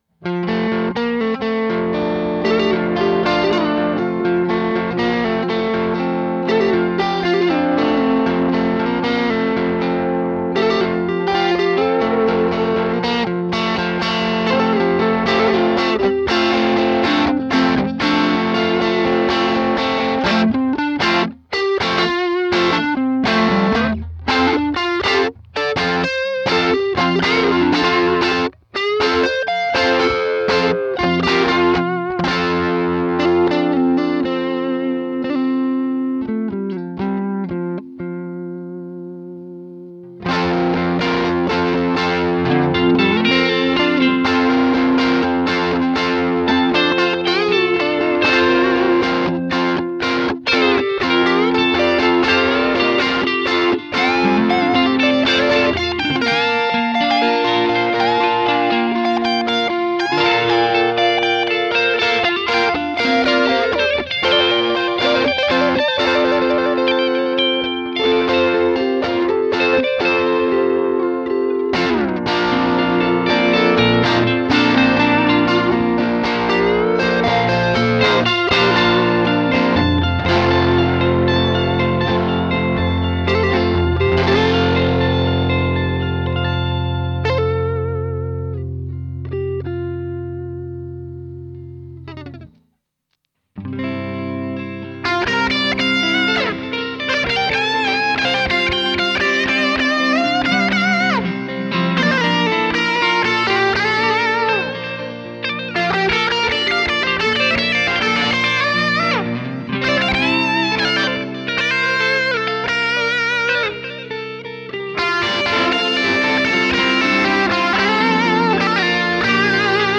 120 BPM
Just riffin on my new tele. 120 bpm
Raw, rootsy, joyous.
Lovin' the looseness / expression.